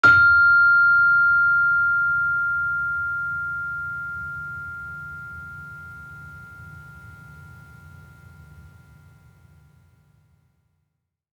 Gender-3-F5-f.wav